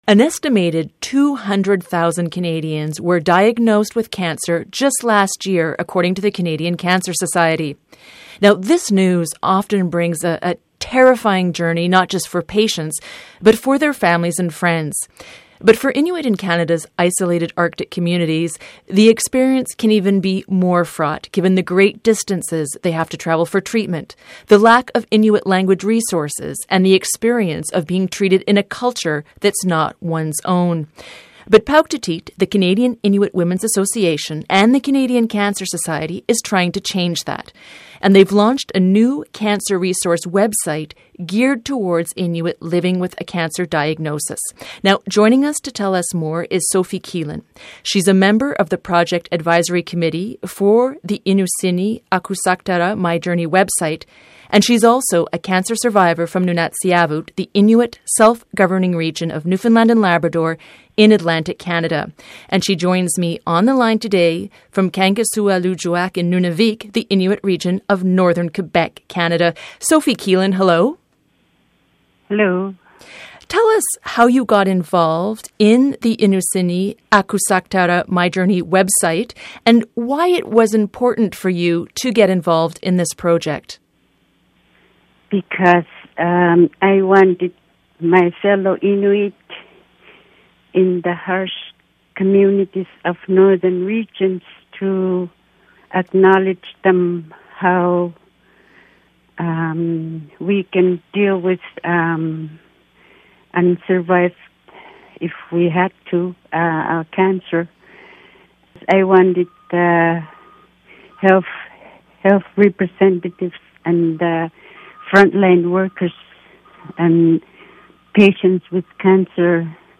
Eye on the Arctic speaks with cancer survivor